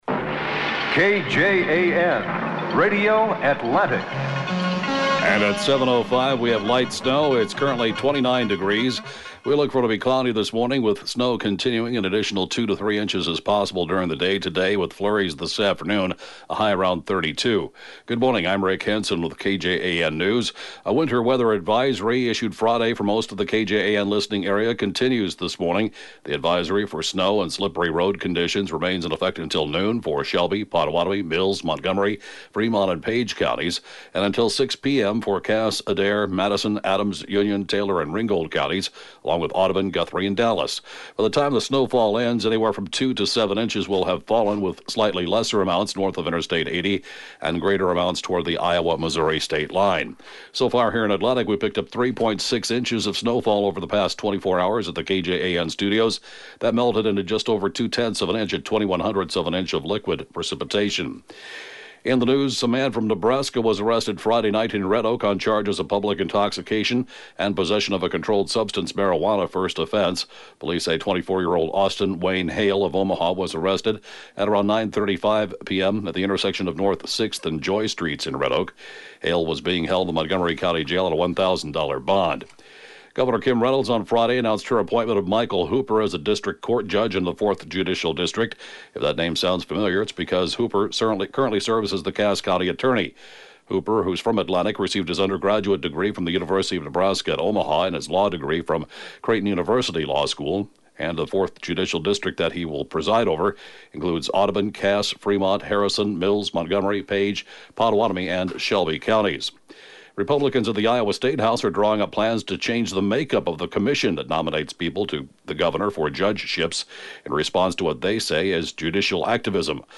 The area’s latest and/or top news stories at 7:06-a.m.